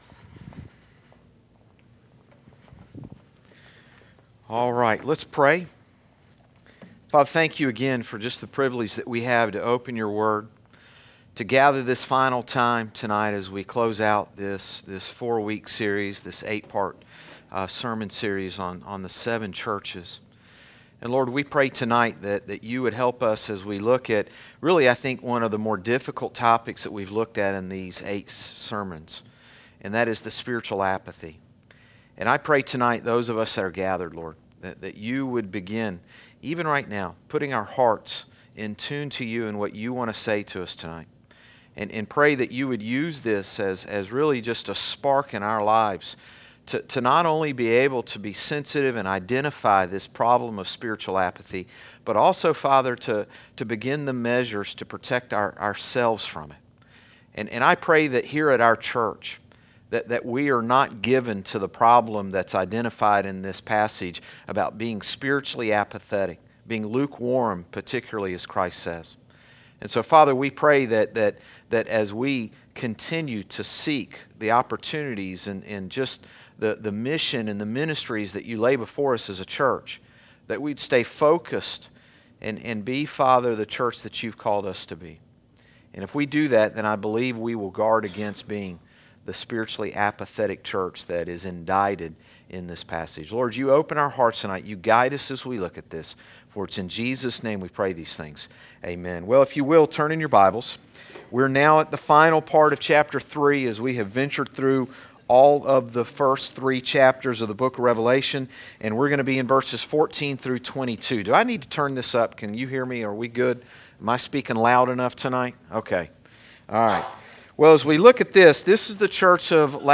Sermons - Concord Baptist Church
Evening-Service-3-3-19.mp3